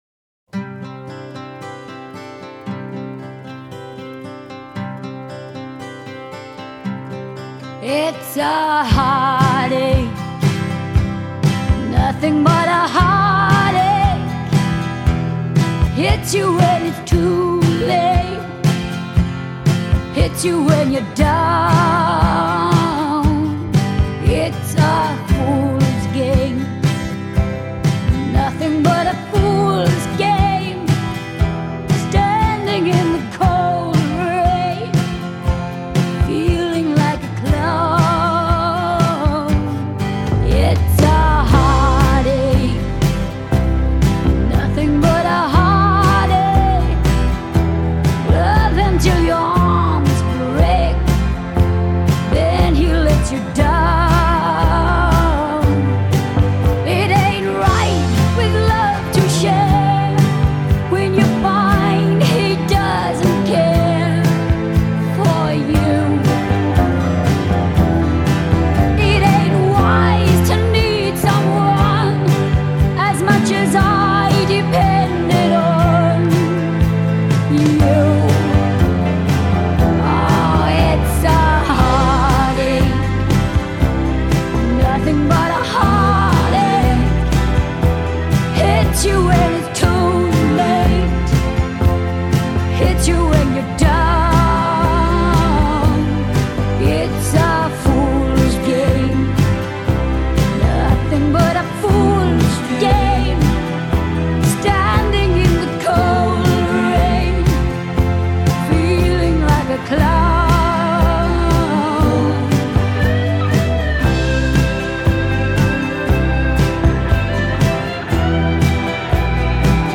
convincingly husky rendition